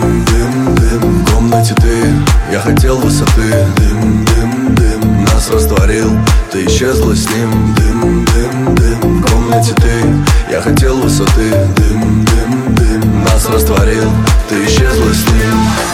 • Качество: 128, Stereo
поп
рэп
зажигательные